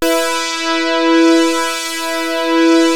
JUP.8 E5   2.wav